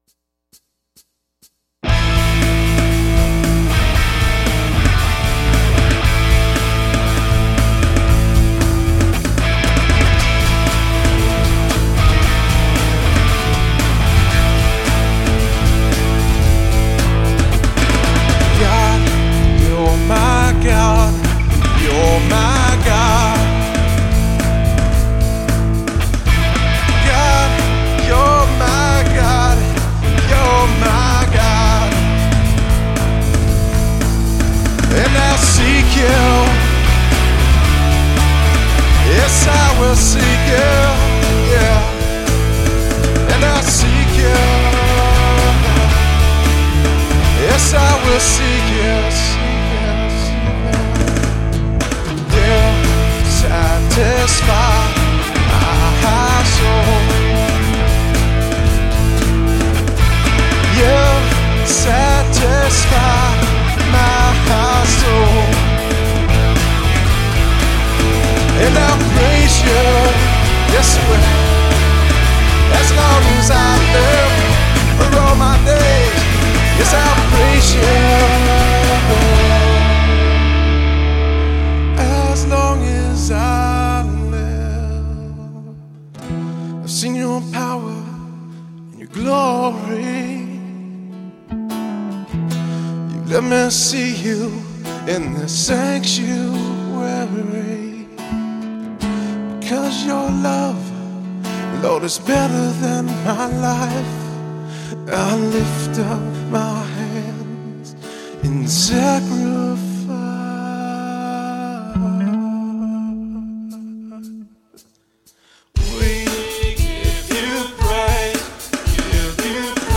A Concert of Prayer
Recorded live at Grace Church At Town Center.
Vocals, Acoustic Guitar
Electric Guitar
Bass, Background Vocals
Drums